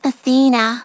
synthetic-wakewords
ovos-tts-plugin-deepponies_Trixie_en.wav